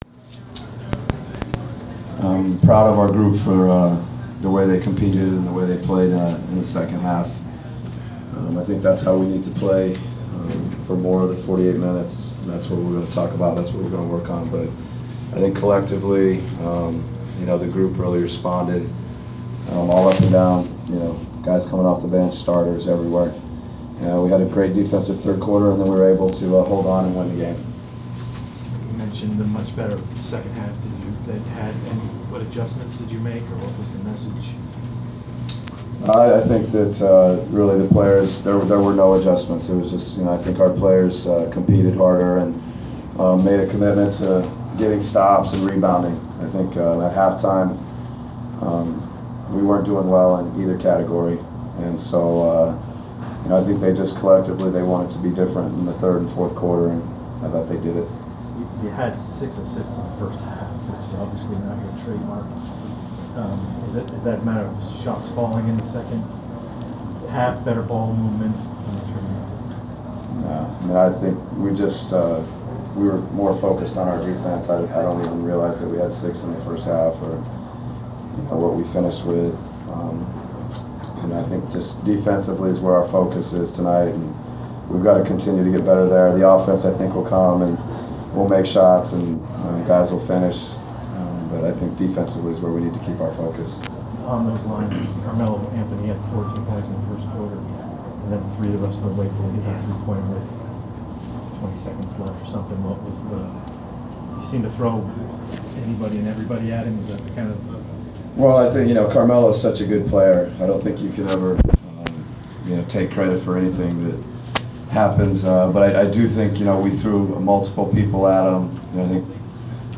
Inside the Inquirer: Interview with Atlanta Hawks’ coach Mike Budenholzer 11/8/14
We caught up with Atlanta Hawks’ coach Mike Budenholzer following the team’s 103-96 win over the New York Knicks on Nov. 8. Topics included defending Carmelo Anthony, improved defense and the second-half turnaround.